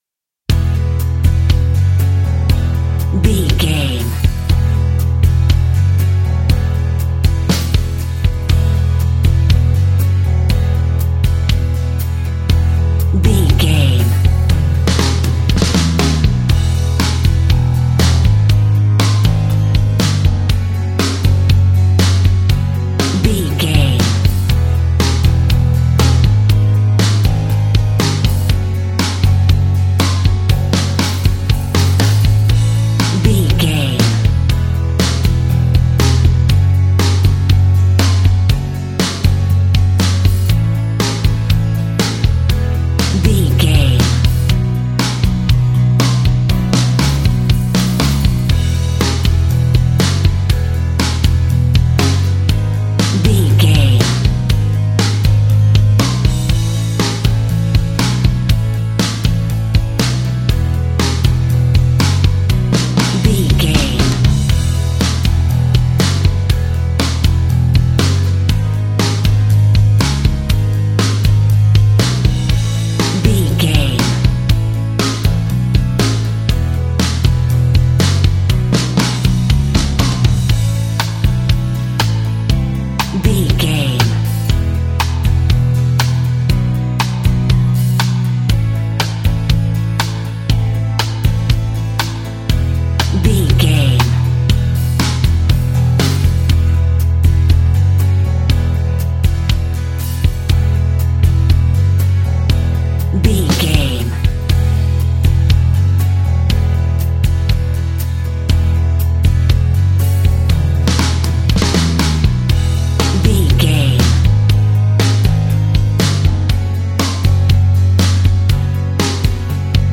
Ionian/Major
pop rock
fun
energetic
uplifting
instrumentals
guitars
bass
drums
organ